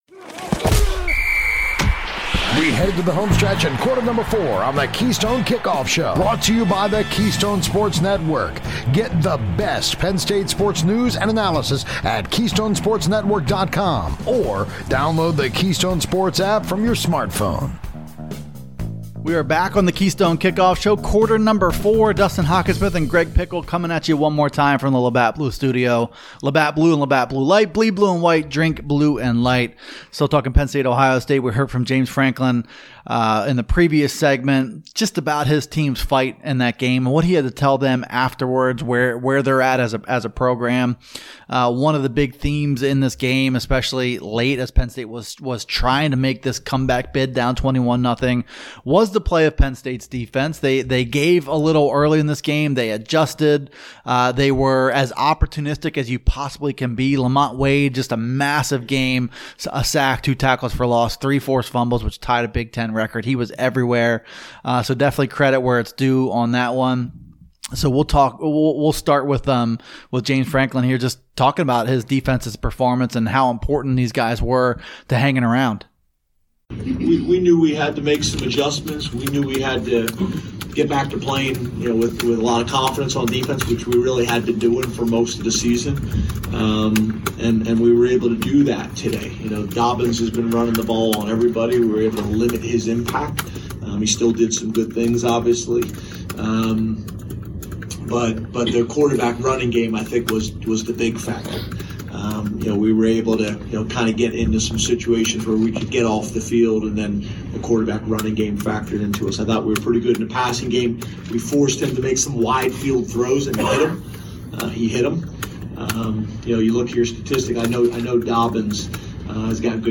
Coach Franklin talks PSU’s defensive effort, blocking Chase Young and more in his postgame presser.